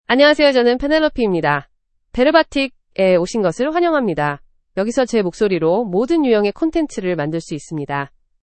PenelopeFemale Korean AI voice
Penelope is a female AI voice for Korean (Korea).
Voice sample
Listen to Penelope's female Korean voice.
Female
Penelope delivers clear pronunciation with authentic Korea Korean intonation, making your content sound professionally produced.